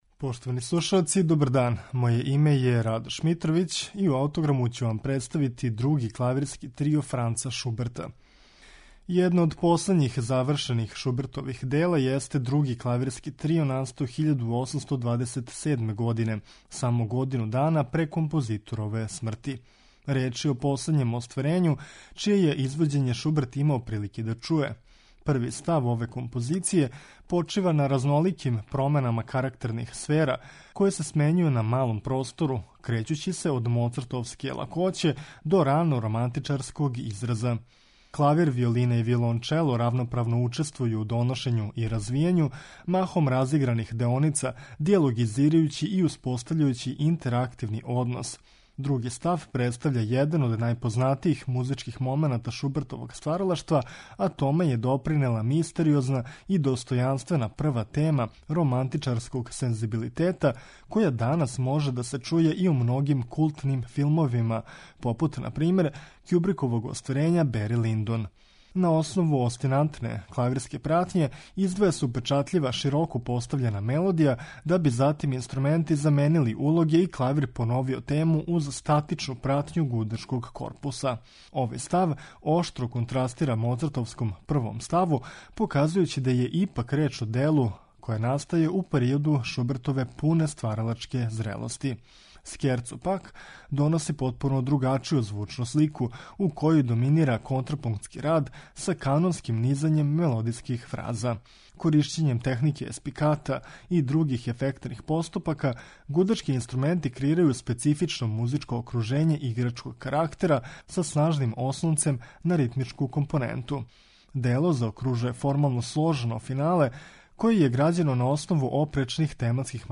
АУТОГРАМ: ФРАНЦ ШУБЕРТ - ДРУГИ КЛАВИРСКИ ТРИО
Ми ћемо га слушати у интерпретацији трија Вандерер.